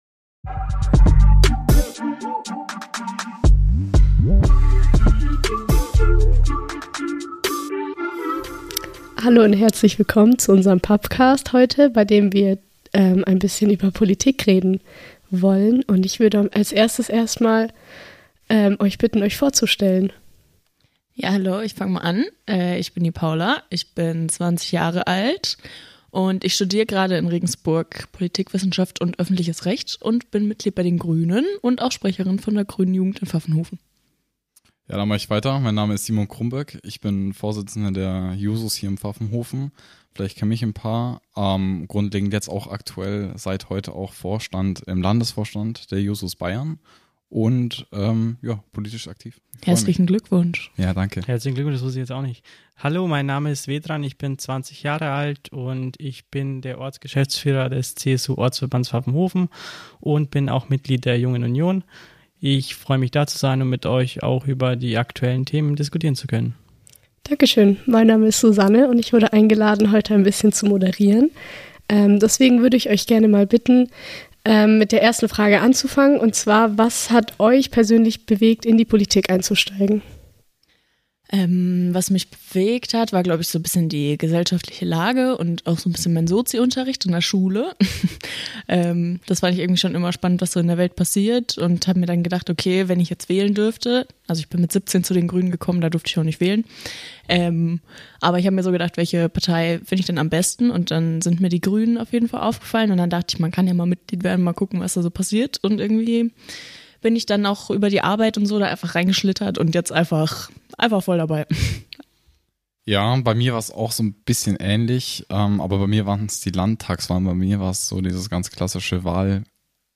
Diskussion zur Wahl